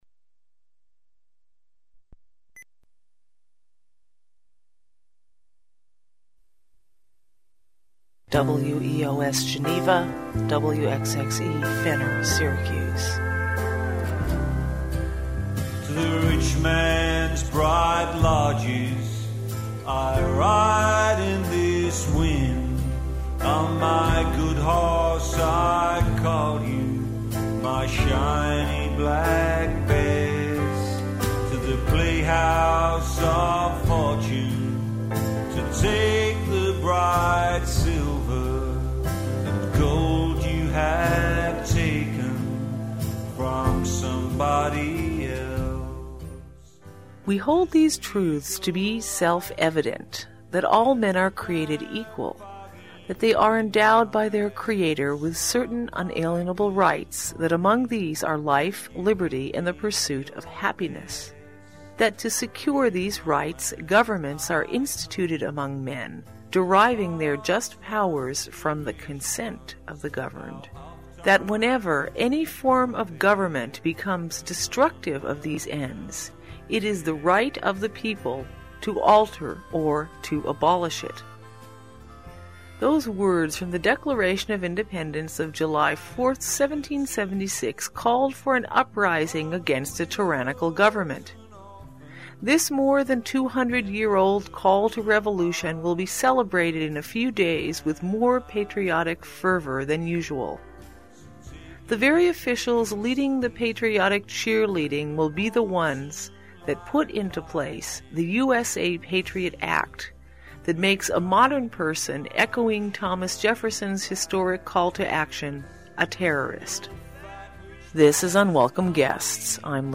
110 - USA Patriot Act: An Assault on Activism (Green Party Panel discussion) - UnwelcomeGuests